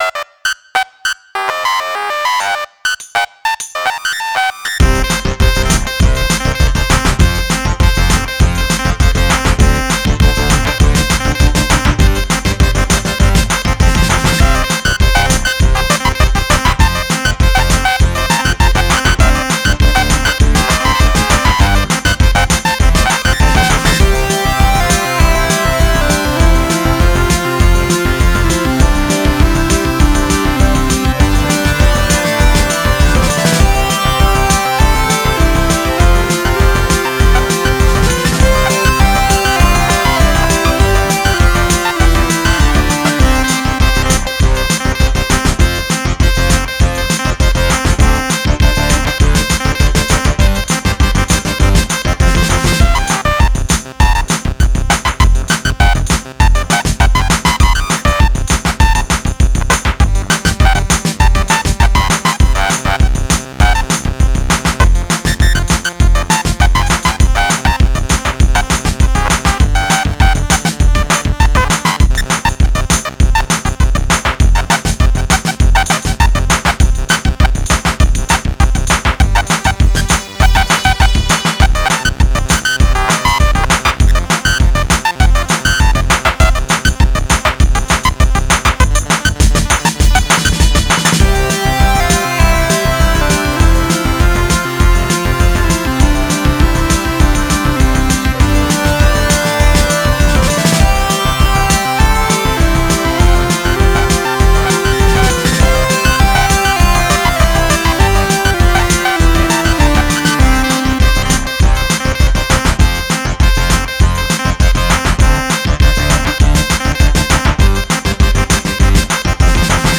Саундтрек из конца кооператива